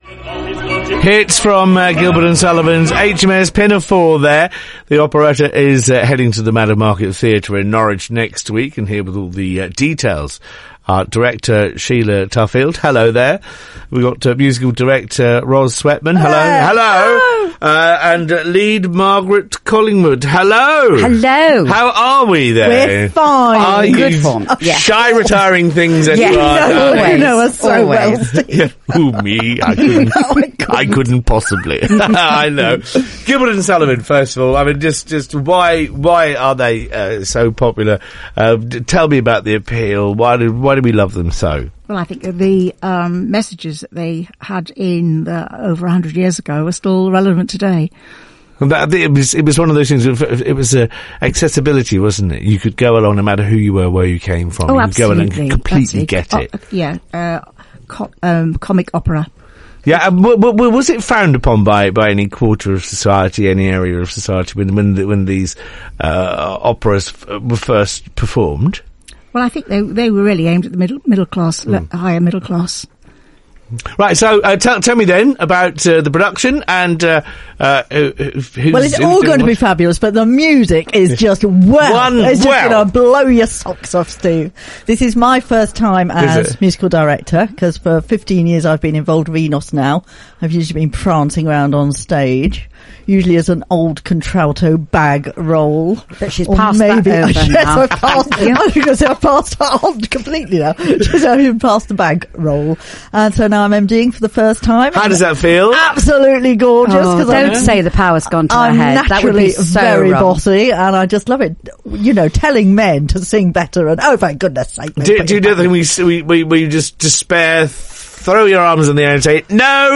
Publicity Interview broadcast on BBC Radio Norfolk on 28 April 2014